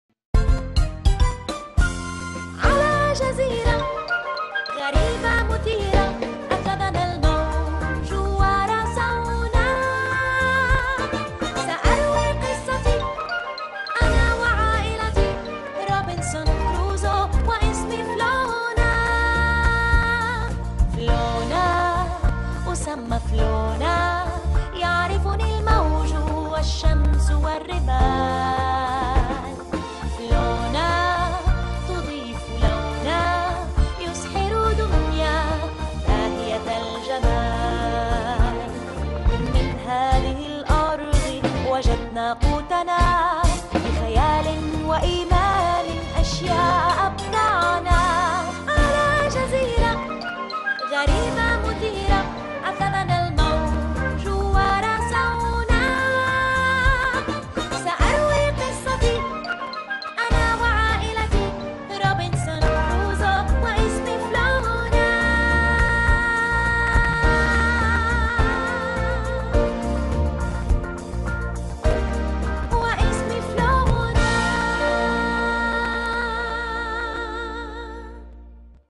فلونة - الحلقة 1 مدبلجة